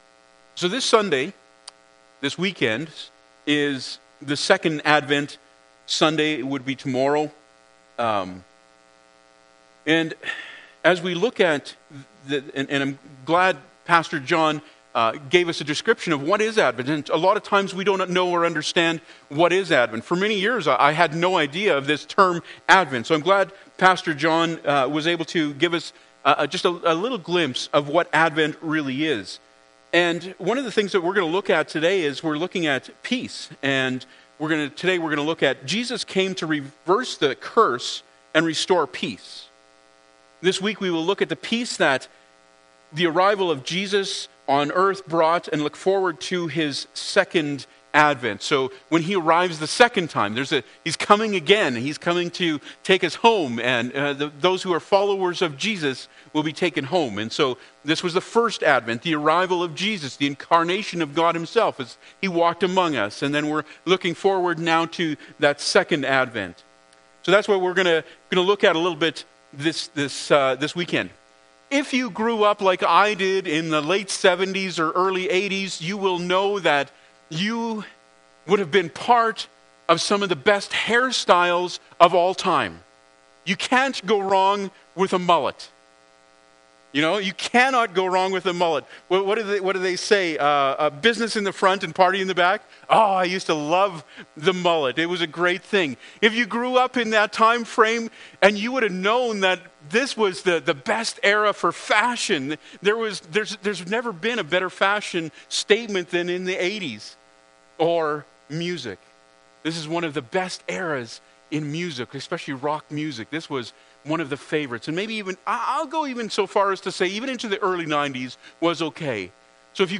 Isaiah 54:10 Service Type: Sunday Morning Bible Text